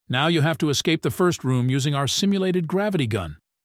anouncer_lvl1_01.ogg